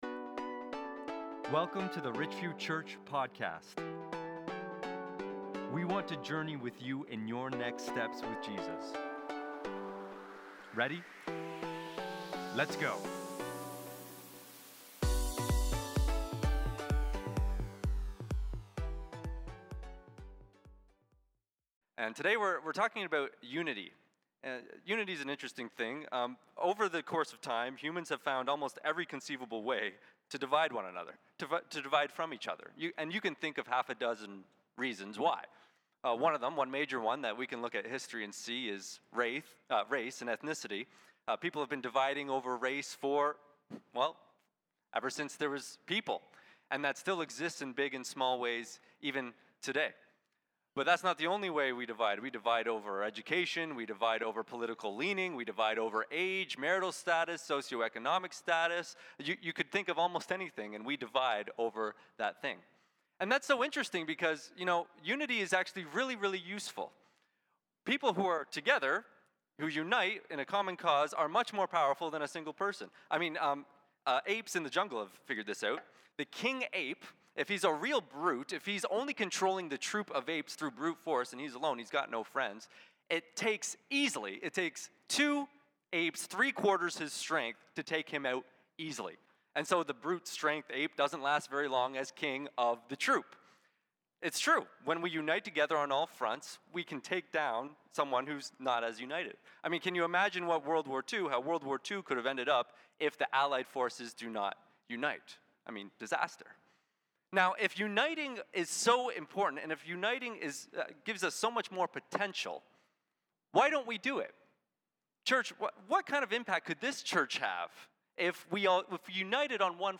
Galatians 3:26-29 💻 Sermon